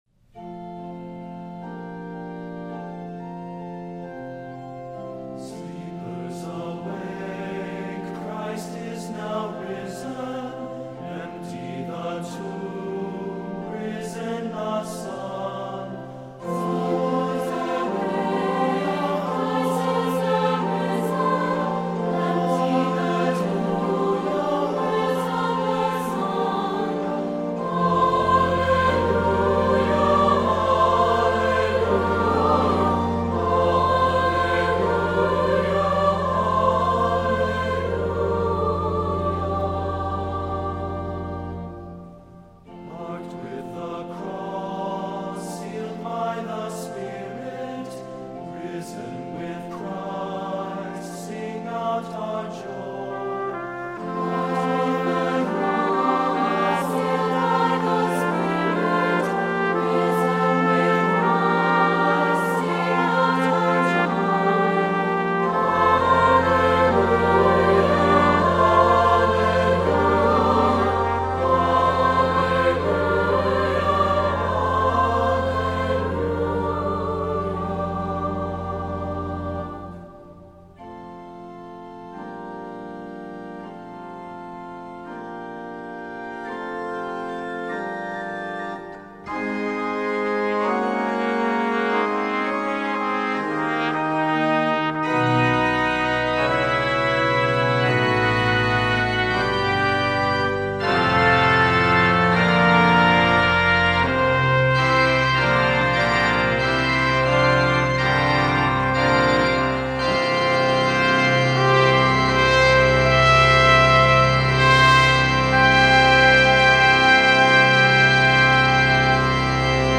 Voicing: 3-part Choir